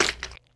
splash2.wav